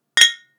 • cheers bottle one Ab Bb.wav
cheers_bottle_one_Ab_Bb_vOy.wav